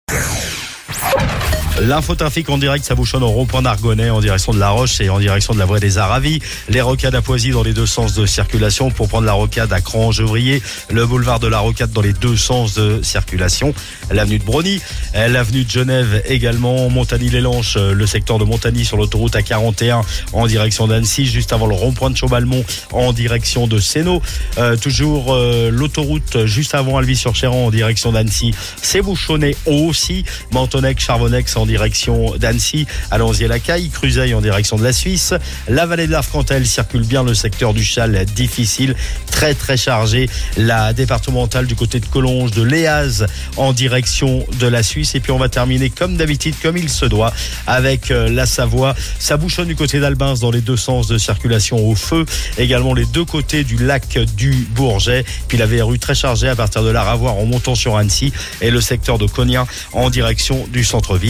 Info trafic